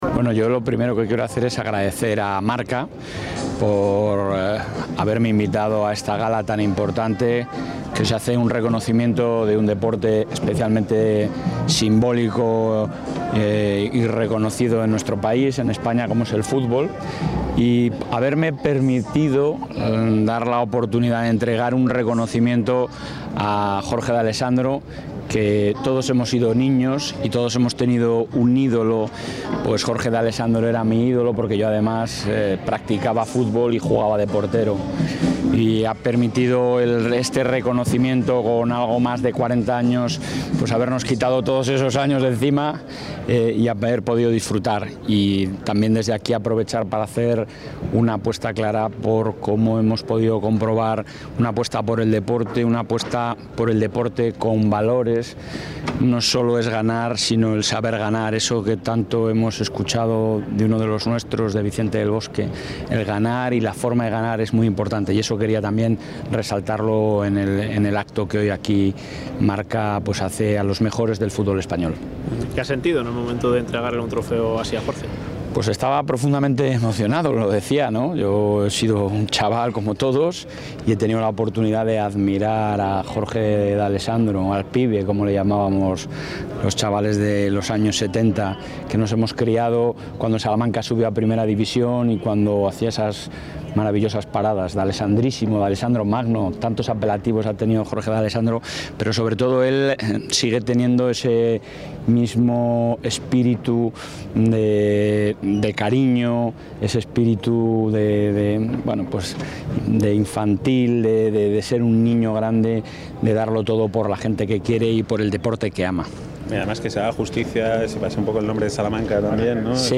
El presidente de la Junta de Castilla y León, Alfonso Fernández Mañueco, ha asistido hoy a la entrega de Premios MARCA de Fútbol 2018-2019.